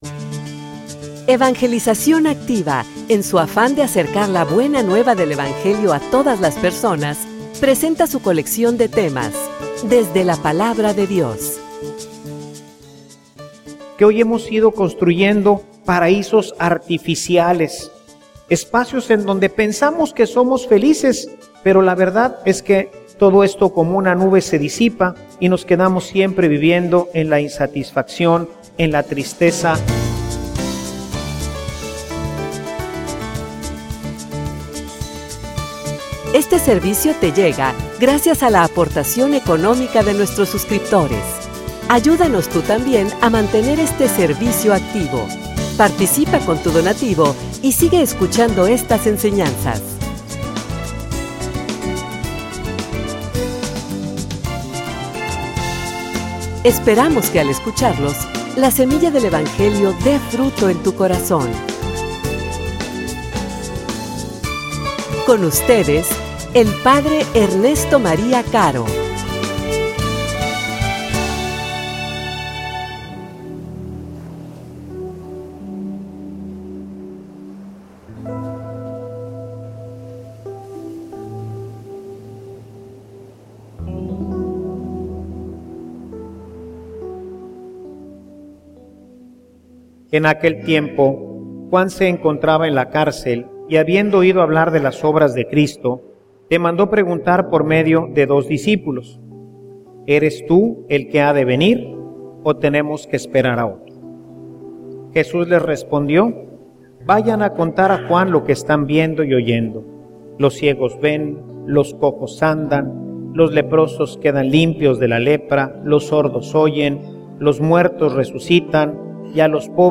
homilia_Una_sonrisa_por_favor.mp3